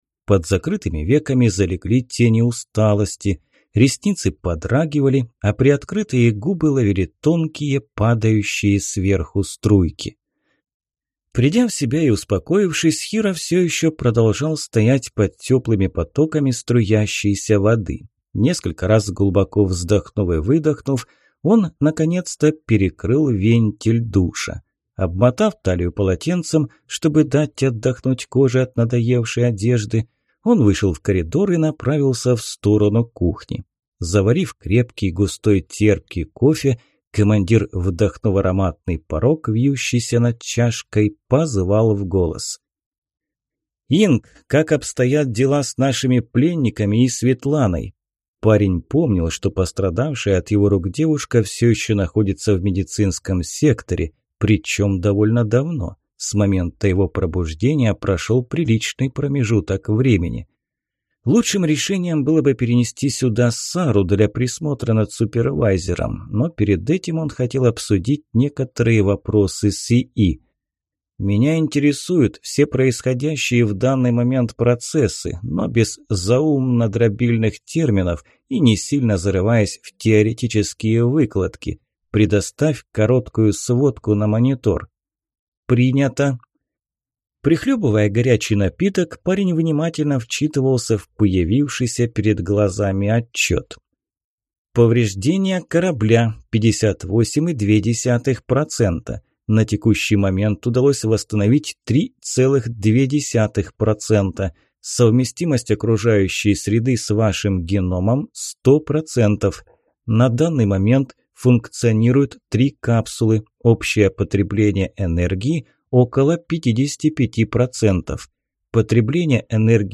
Аудиокнига Моя Космическая Станция. Книга 2. Охота за кристаллами | Библиотека аудиокниг